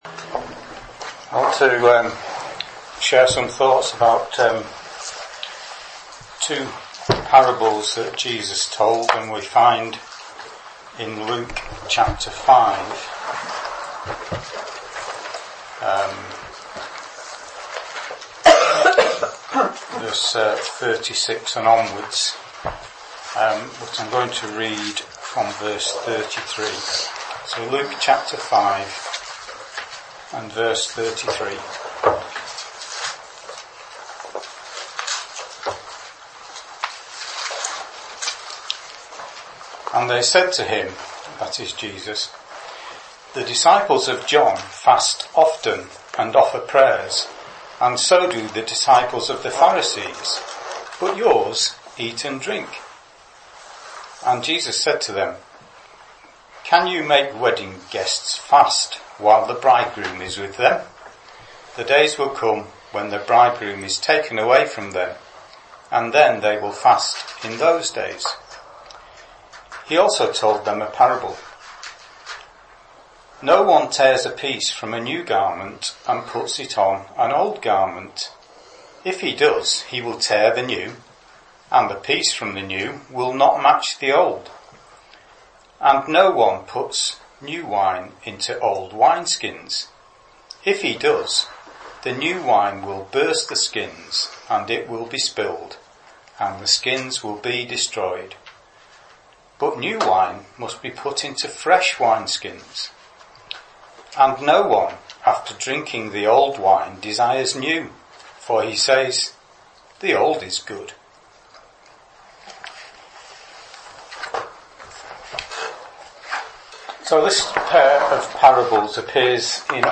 Sermon Podcasts Downloads | Salem Chapel, Martin Top